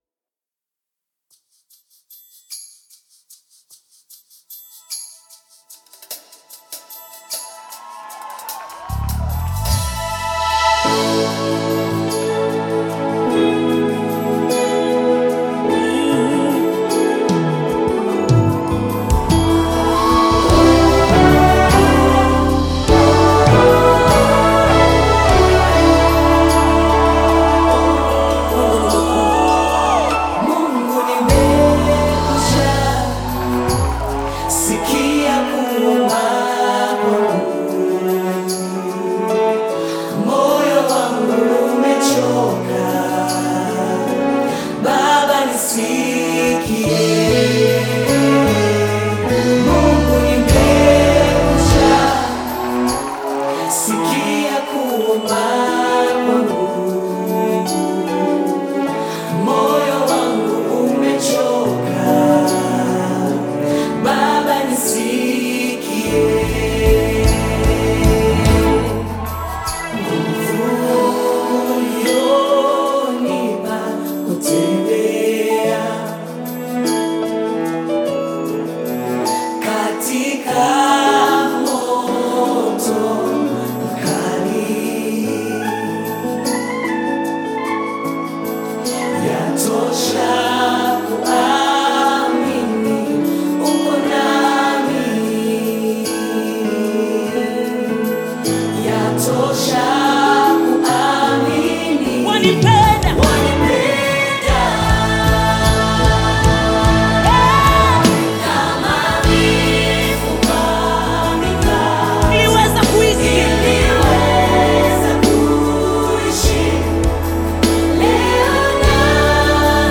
Gospel music track
Tanzanian gospel